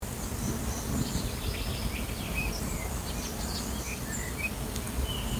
Audioaufnahmen aus dem Schutzgebiet